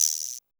CR78 GUIRO.wav